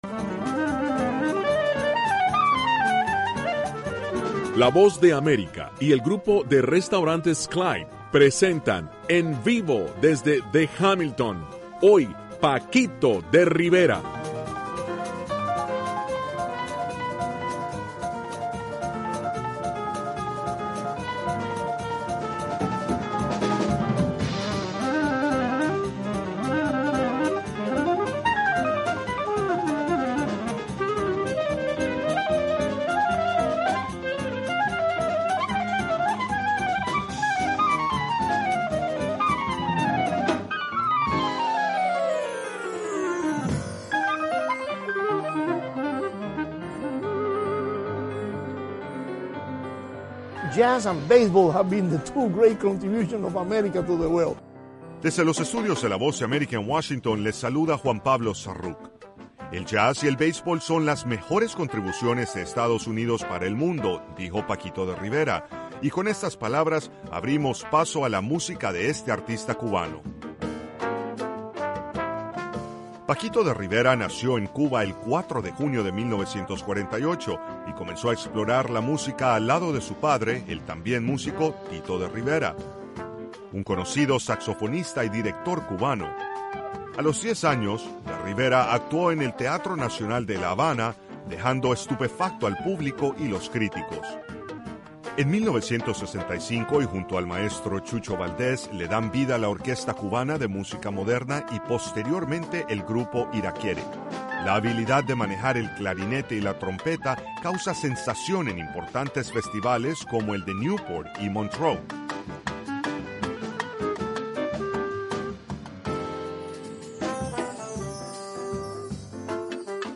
Diez minutos de noticias sobre los acontecimientos de Estados Unidos y el mundo.